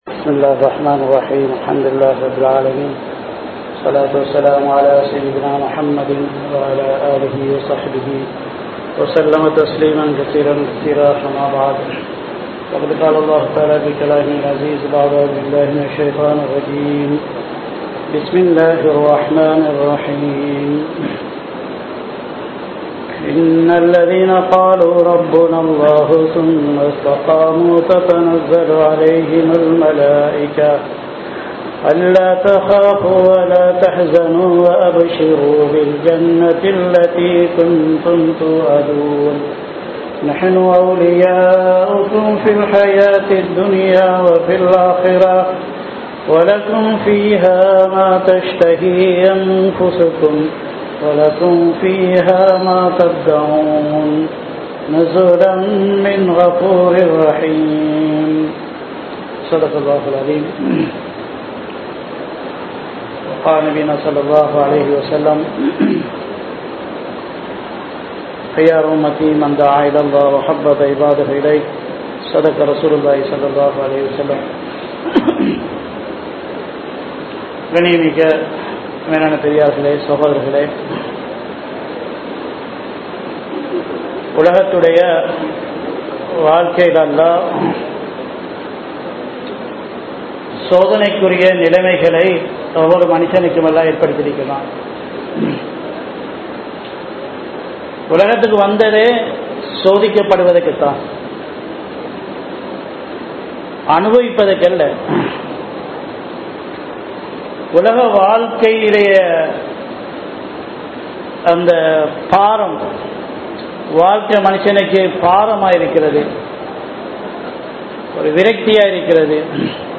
வாழ்க்கையின் நோக்கம் | Audio Bayans | All Ceylon Muslim Youth Community | Addalaichenai
Gothatuwa, Jumua Masjidh 2025-04-24 Tamil Download